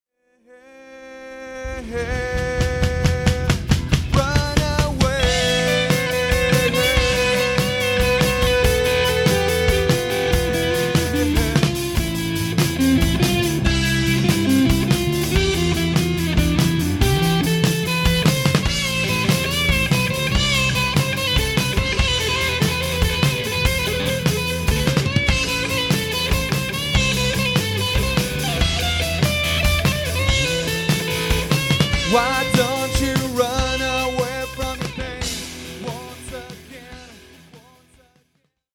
Forever a Guitarist Forever a Guitarist Facebook Abandoner Facebook Abandoner Disclaimer Disclaimer
runaway_live_setubal_solo.mp3